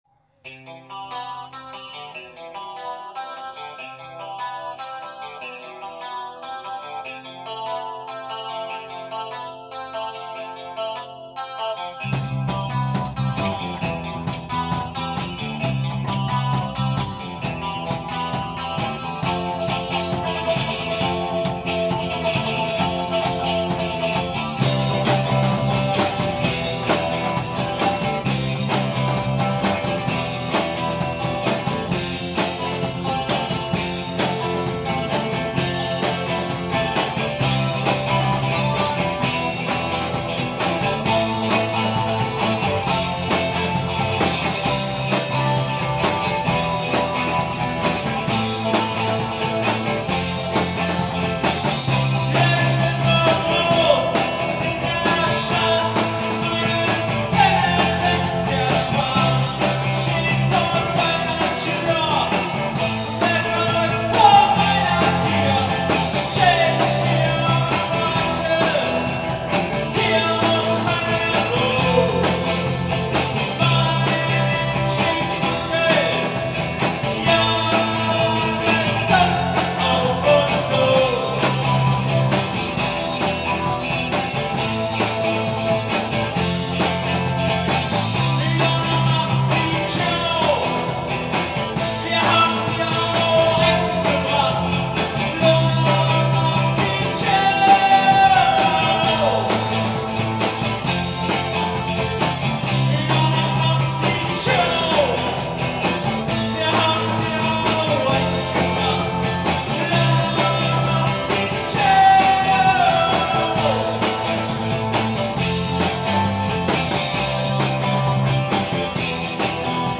Wave-Gothic-Indie-Band